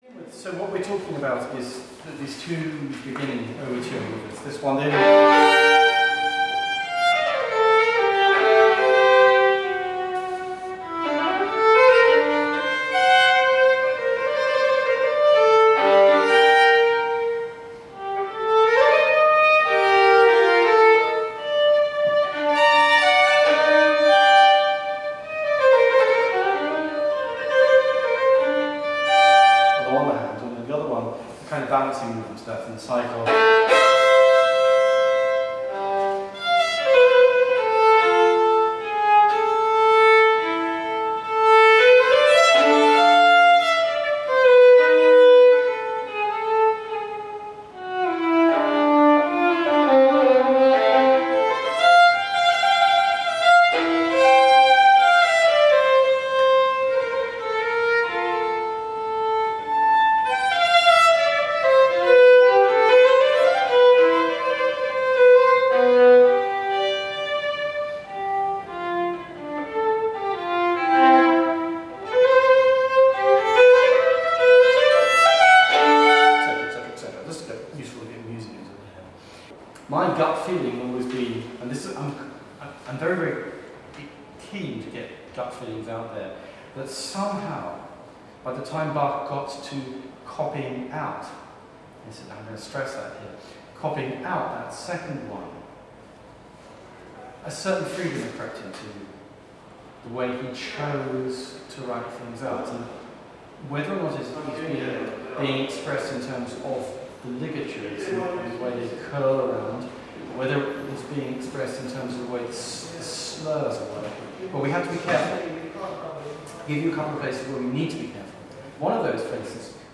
18th September 2004 Royal Academy of Music Museum